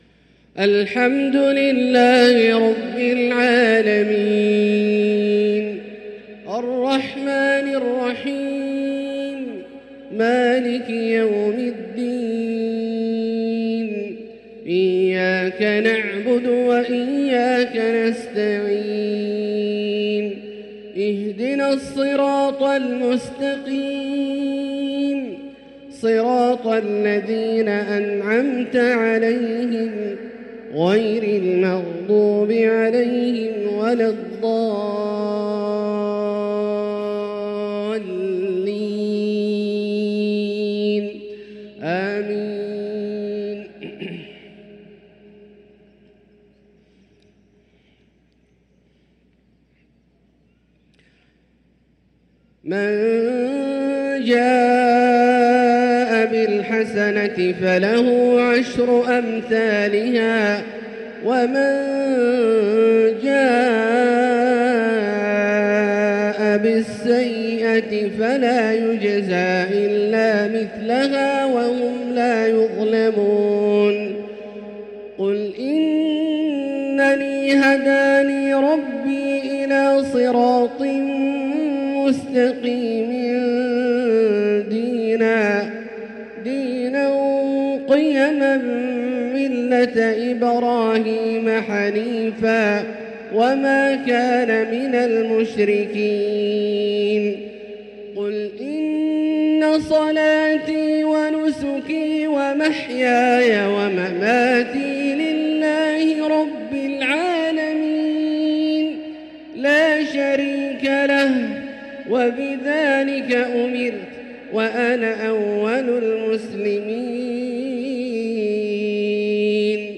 Isha prayer from Surah Al-Anaam& al naml 5/1/2024 > 1445 H > Prayers - Abdullah Al-Juhani Recitations